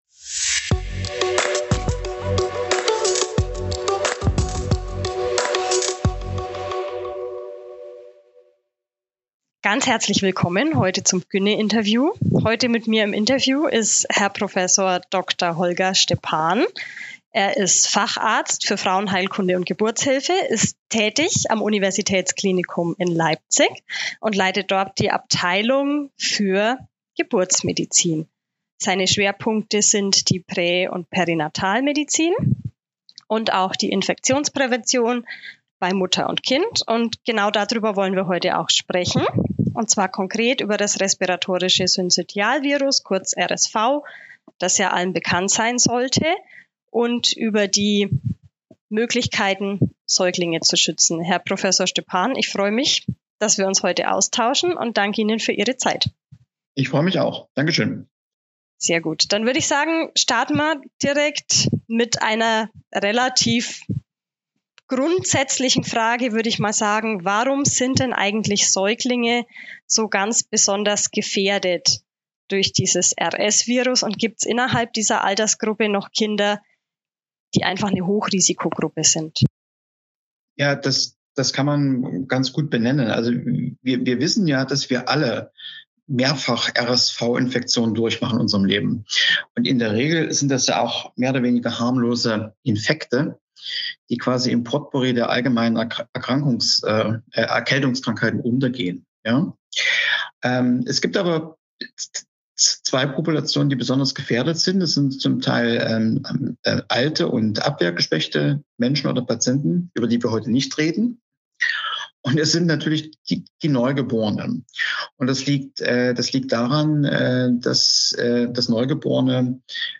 Expertentalk ~ mgo medizin Podcast